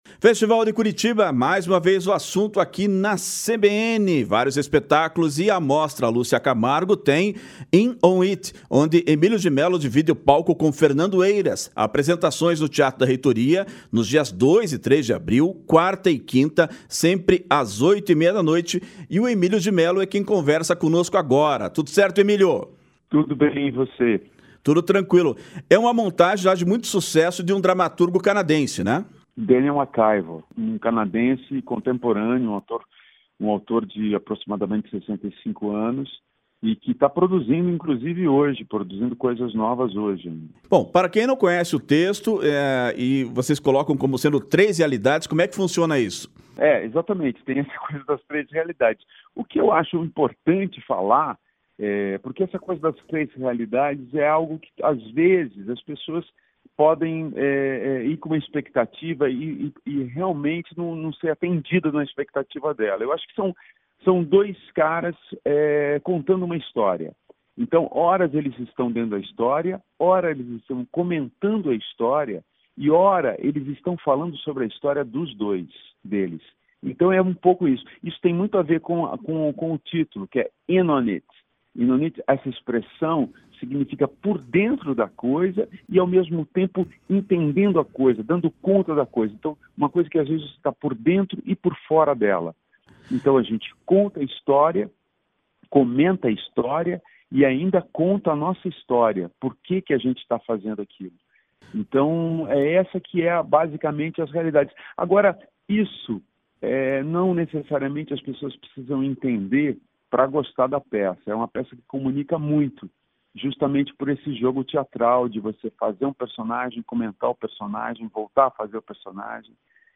Festival de Curitiba: Emílio de Mello fala sobre a peça “In On It” e recorda parceria com ator curitibano